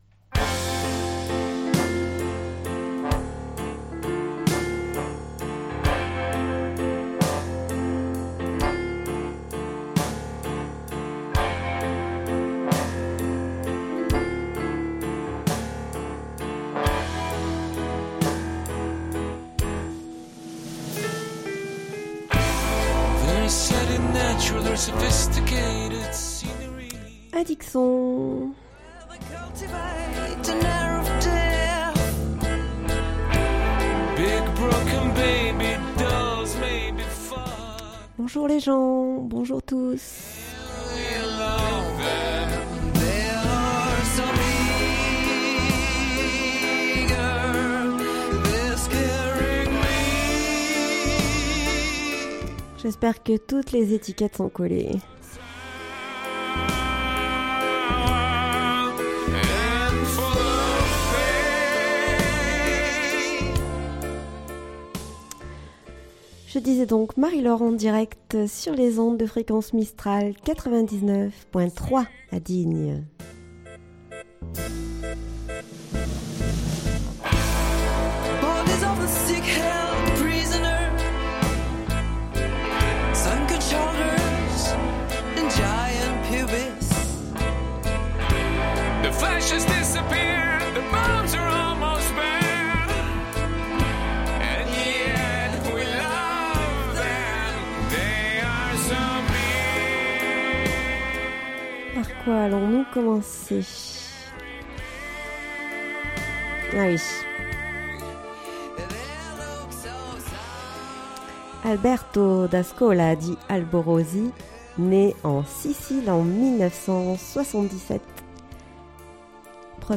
Addic son, une émission musicale proposée